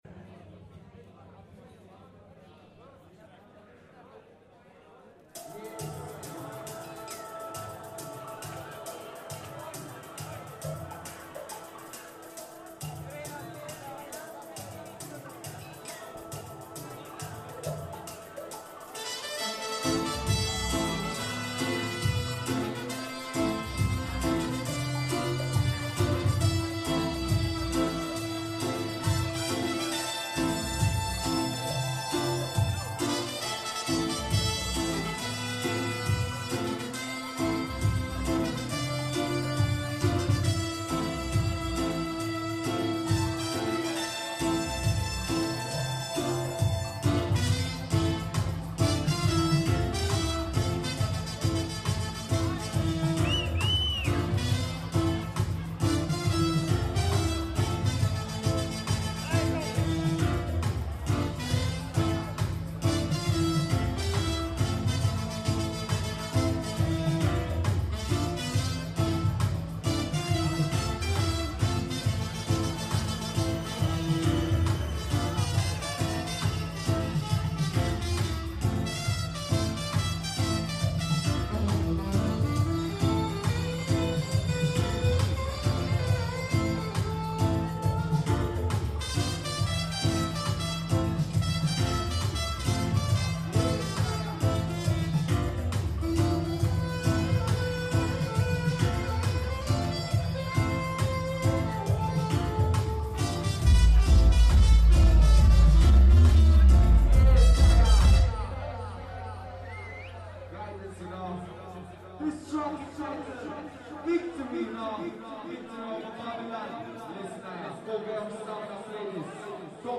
strictly vinyl